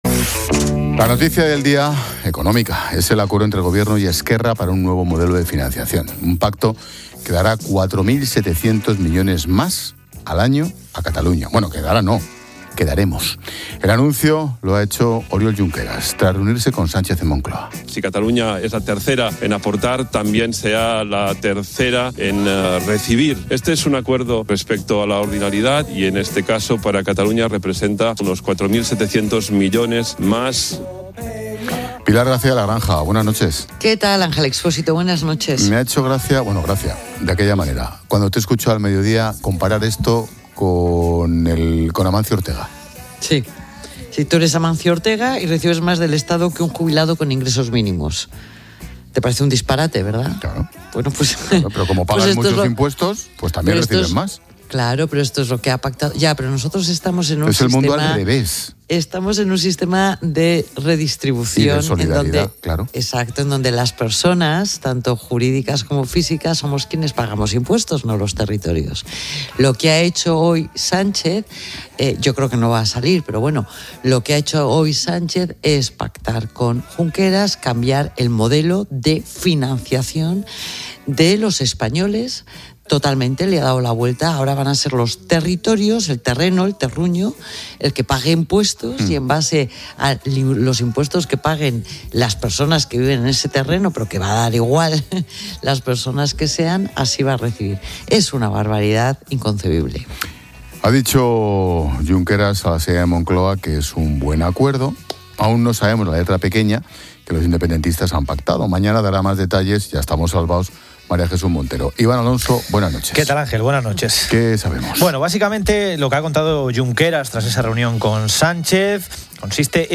El anuncio se ha producido tras una reunión con el presidente Pedro Sánchez en Moncloa, y ha sido analizado en profundidad en el programa La Linterna de COPE por un panel de expertos económicos.